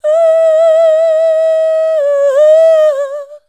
UUUH 2.wav